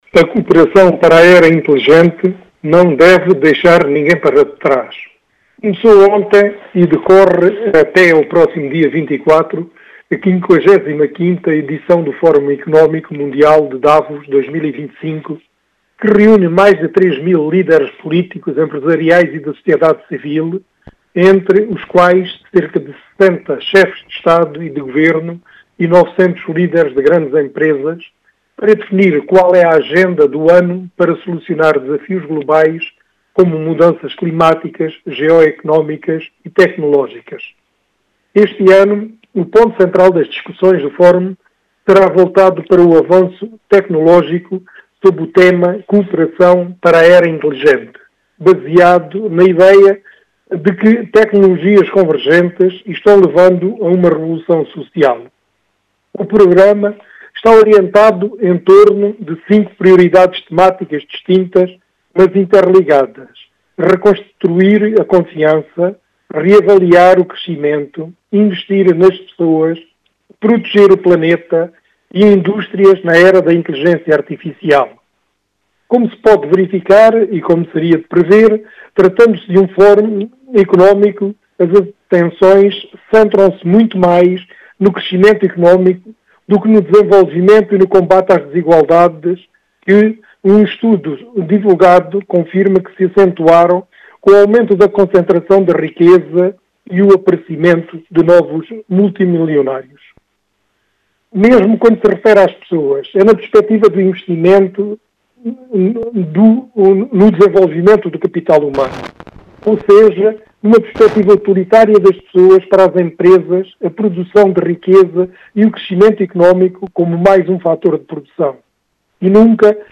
Crónica de Opinião